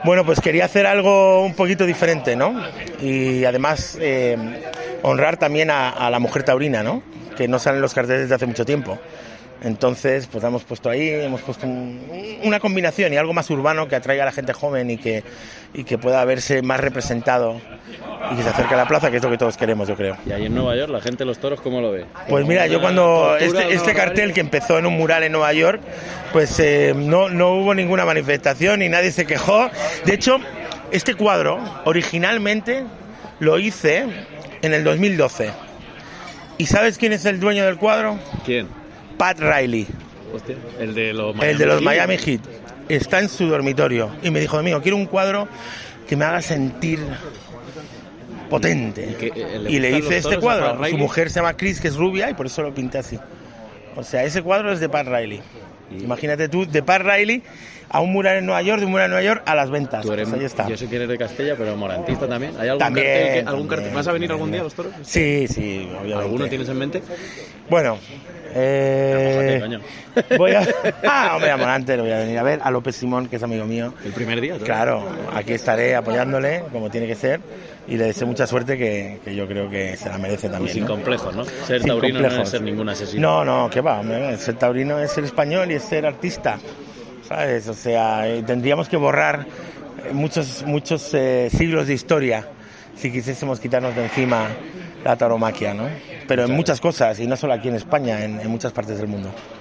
Zapata destacó en los micrófonos de El Albero el curioso recorrido del cuadro: “Ha pasado de Pat Riley a un mural en Nueva York hasta llegar a Las Ventas”.